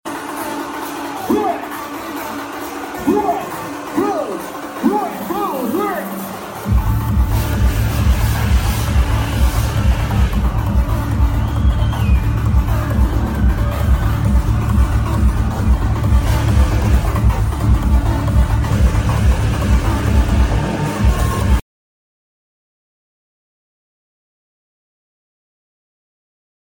Crowd and energy were unreal.